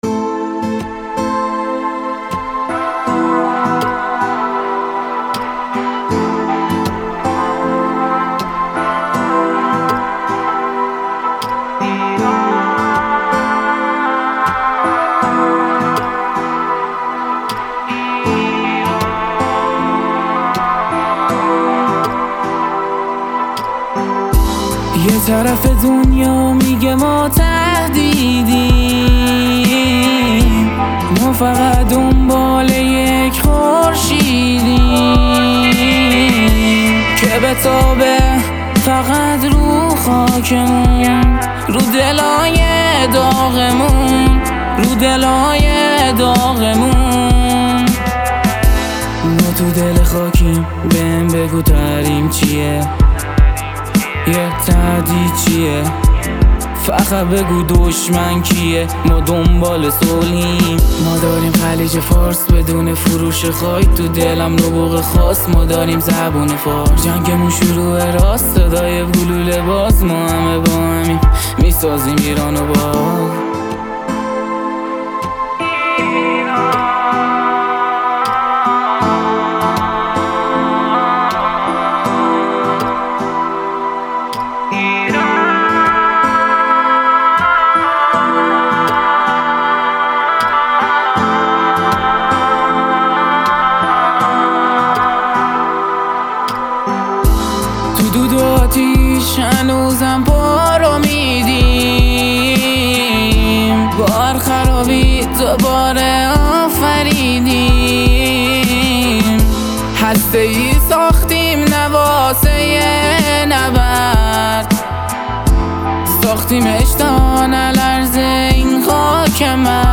رپ فارس RnB